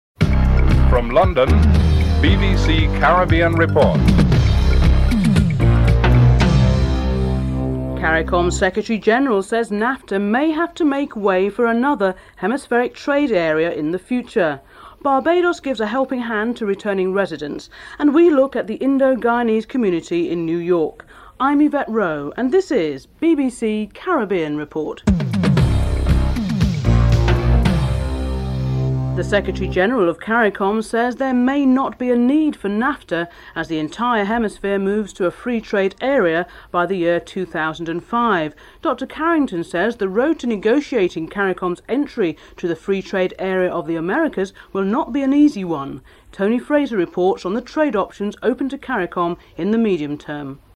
Prime Minister Owen Arthur is interviewed
Several Indo-Guyanese are interviewed (10:12-13:54)